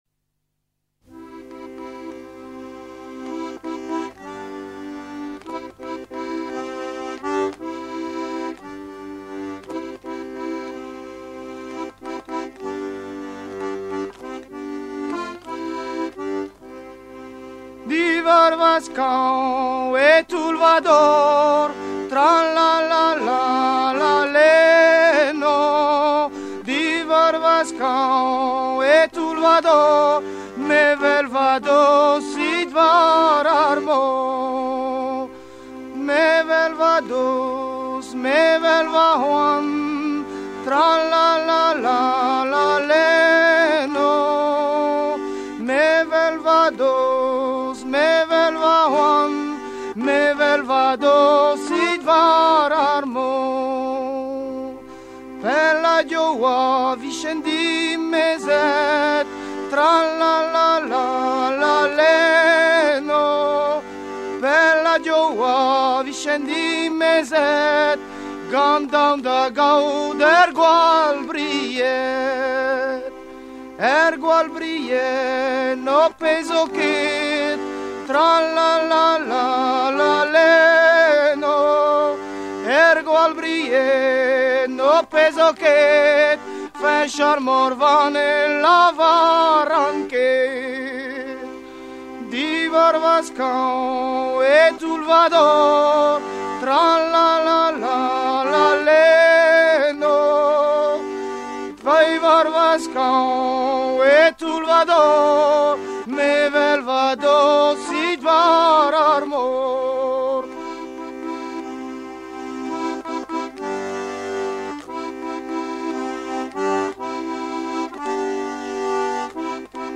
Edition discographique Chants de marins traditionnels, vol. I à V
Catégorie Pièce musicale éditée